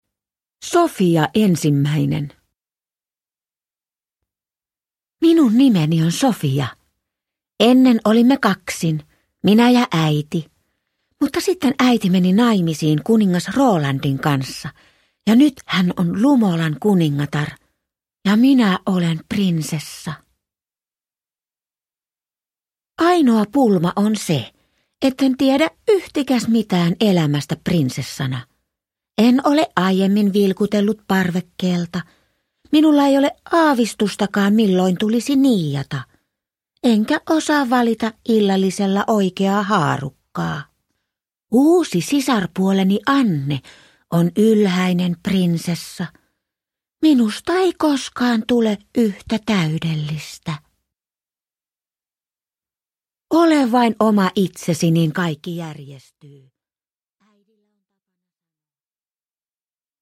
Sofia ensimmäinen – Ljudbok – Laddas ner